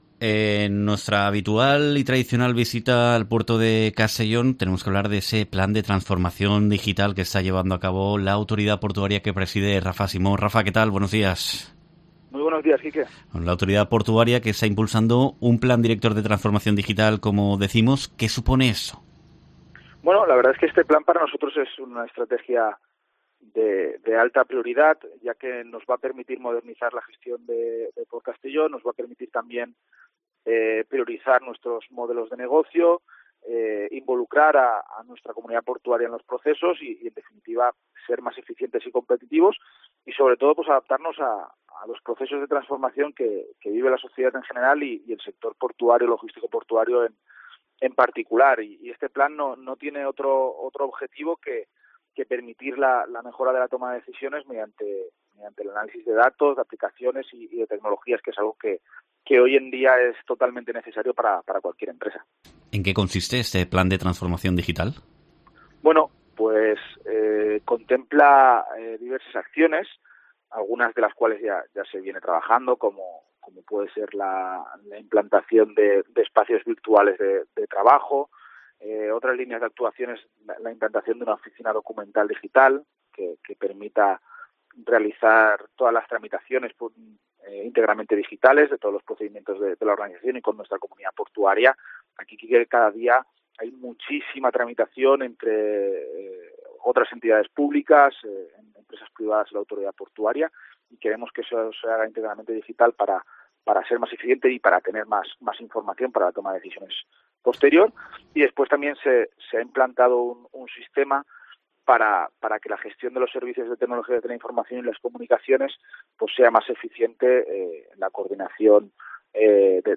Entrevista
PortCastelló trabaja en su Plan de Transformación Digital, según reconopce en COPE el presidente de la Autoridad Portuaria, Rafa Simó